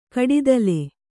♪ kaḍidale